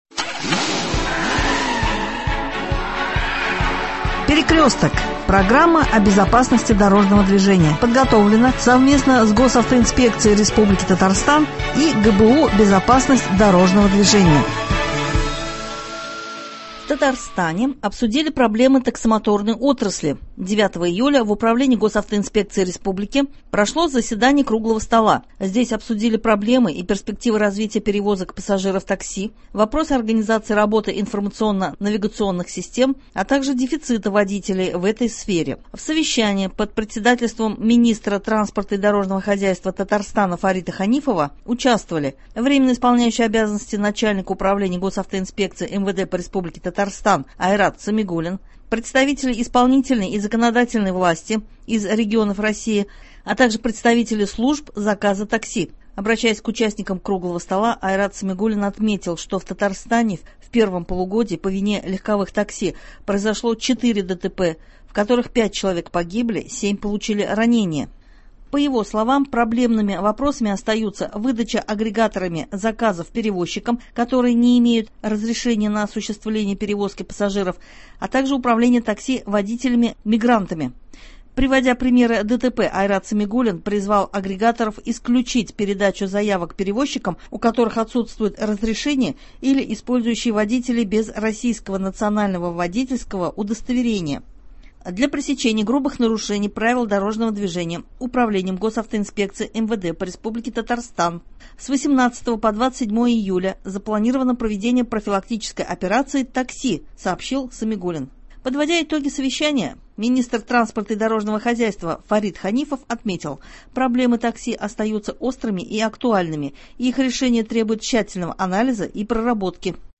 Выступление Эльнара Садриева — начальника Отдела ГАИ УМВД России по Казани о нарушителях ПДД, управляющих в состоянии алкогольного опьянения